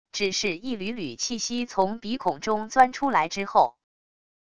只是一缕缕气息从鼻孔中钻出来之后wav音频生成系统WAV Audio Player